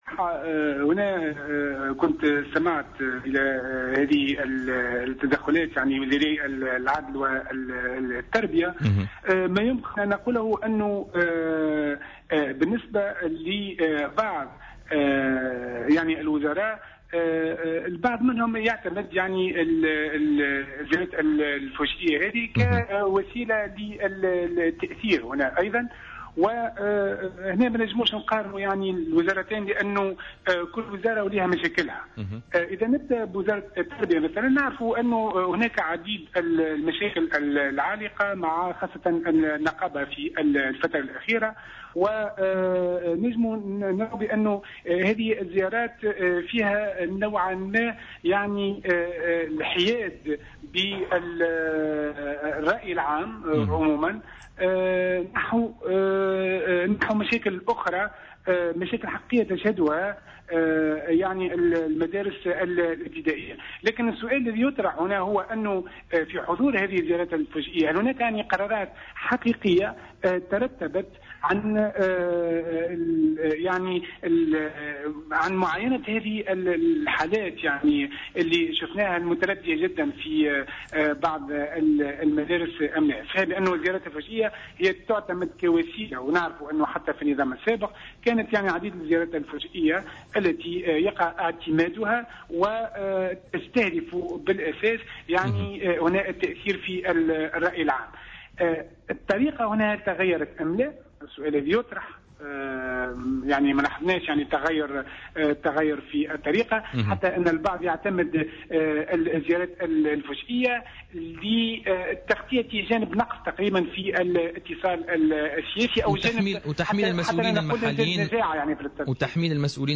ورأى في حوار مع "الجوهرة اف أم" في برنامج "بوليتيكا" أن الزيارات الفجئية التي يتم الإعلان عنها لمسؤولين حكوميين لم تغير من الواقع بشيء، مرجحا أن يكون مرد هذا الأمر هو التهرّب من المسؤولية. وأضاف أن بعض الوزراء يعتمدون هذه السياسة الاتصالية القديمة كوسيلة للتأثير على الرأي العام، مؤكدا أن هذه الطريقة لم تعد مواكبة للتطور التكنولوجي لتقنيات الاتصال.